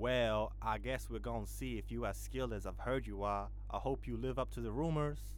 Voice Lines
Well I guess we_re gonna see if youre as skilled.wav